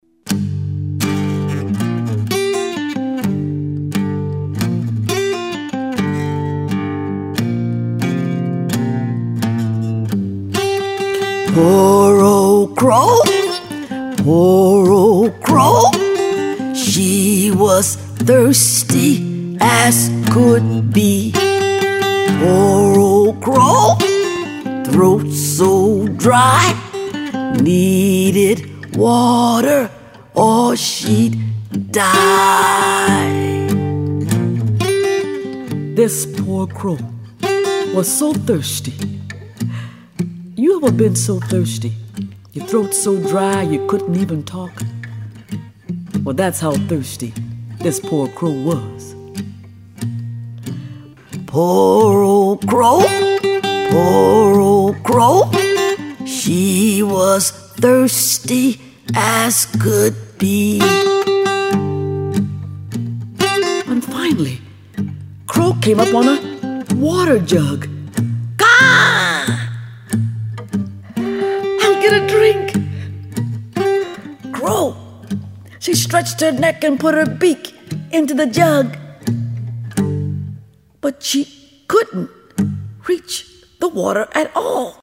on vocals, banjo, guitar, and bass
. . . . .  All in all, this is one of the most entertaining and best produced storytelling